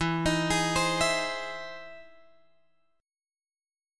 E Augmented Major 7th